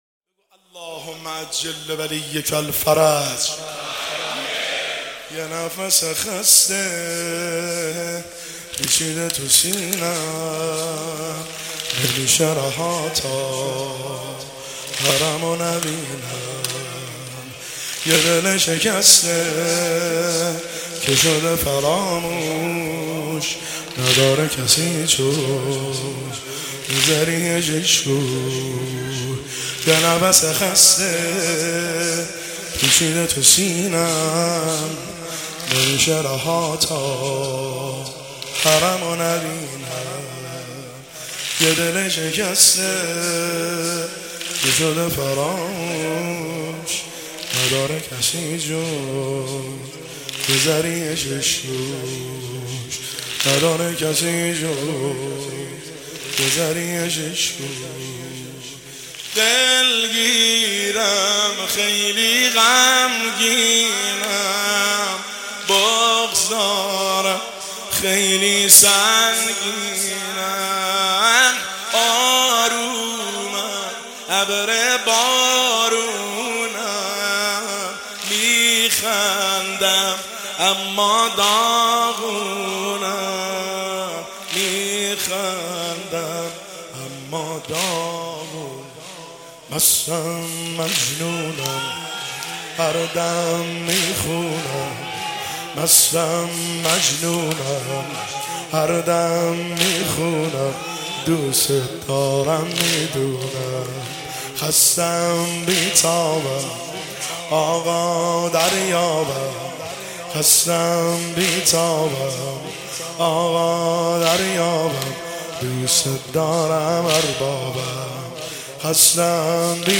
سنگین  شب هفتم محرم الحرام 1404
هیئت خادم الرضا قم